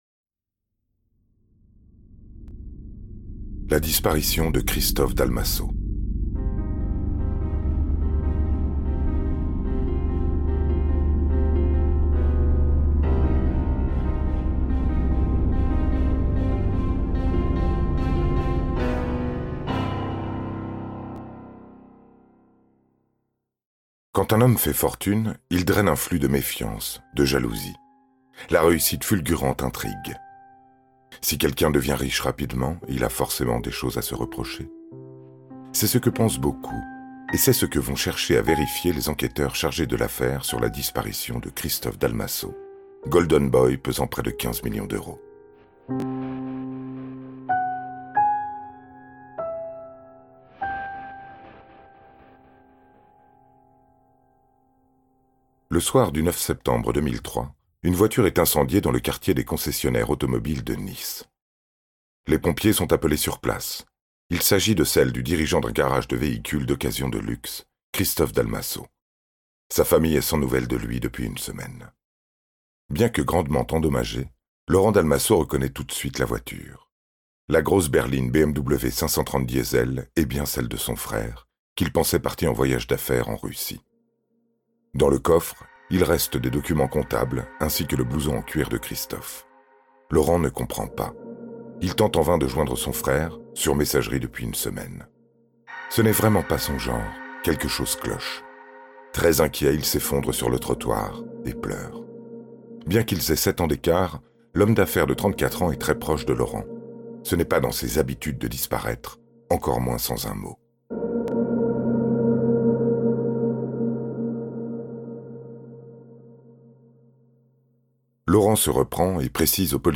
Extrait gratuit - Crimes en Provence volume 2 de Collectif